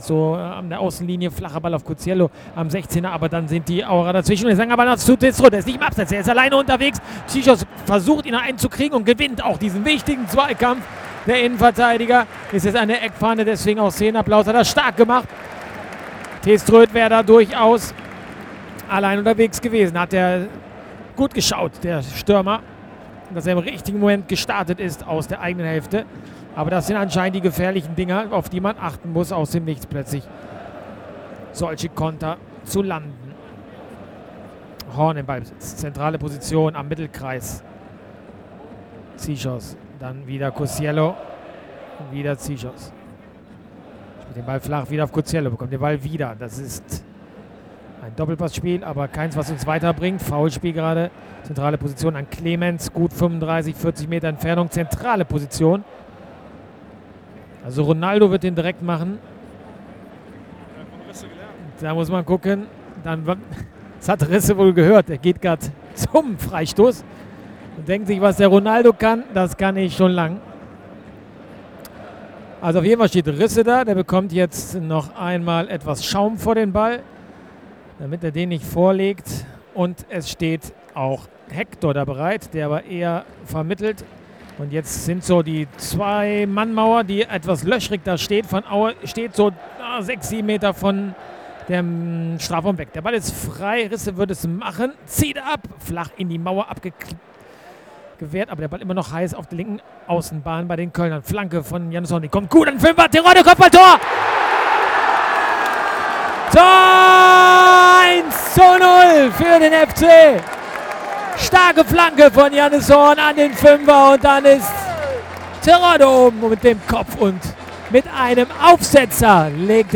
Hörprobe: Live-Blindenreportage eines Spiels des 1. FC Köln